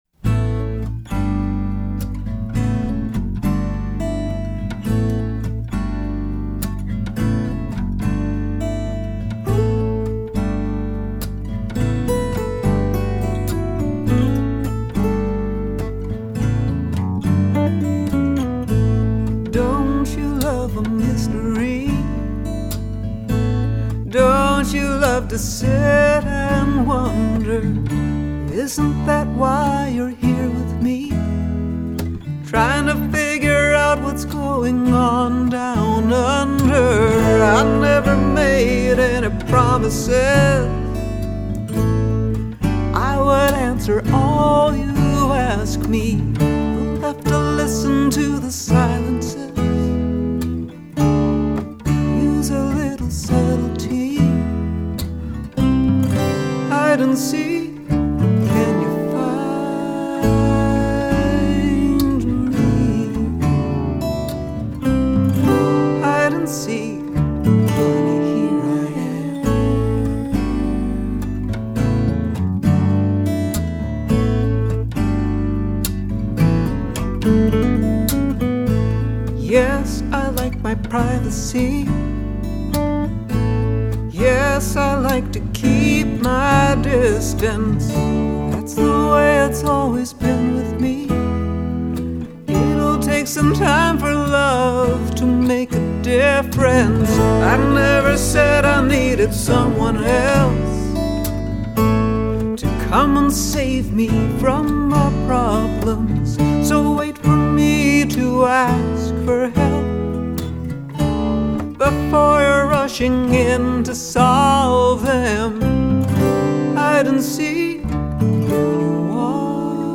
Pop, Folk